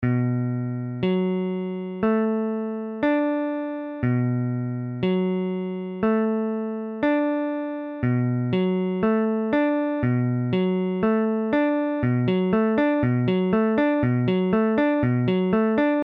Tablature Bm7.abcBm7 : accord de Si mineur septième
Mesure : 4/4
Tempo : 1/4=60
A la guitare, on réalise souvent les accords de quatre notes en plaçant la tierce à l'octave.
Bm7.mp3